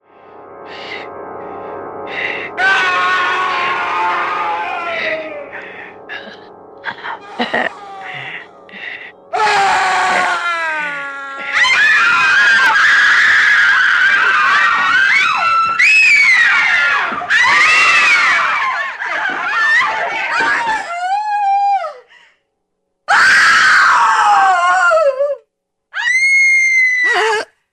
Звуки ужаса или для создания эффекта чего-то ужасного для монтажа видео
12. Ужасы